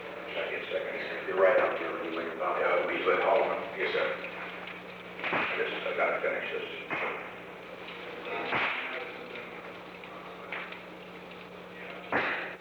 Secret White House Tapes
Conversation No. 662-3
Location: Oval Office
The President met with Alexander P. Butterfield.